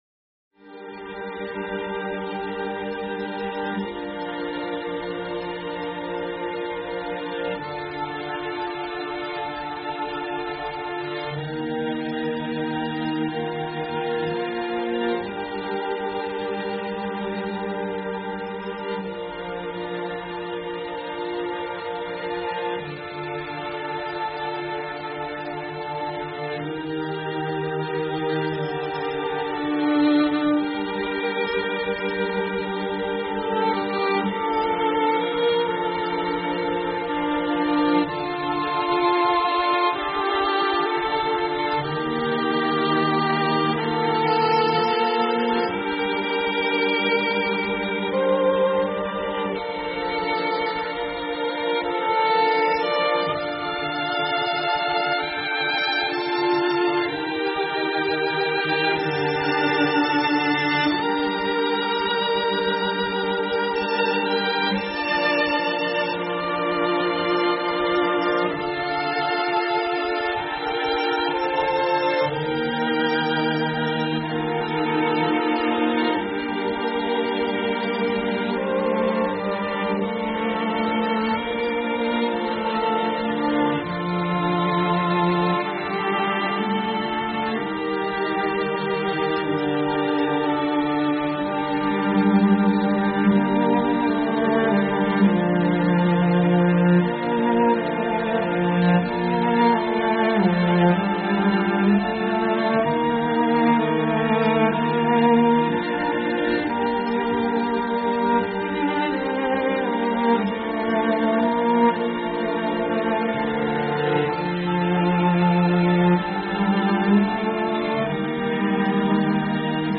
Под эту песню тока плакать sad Грусно!!!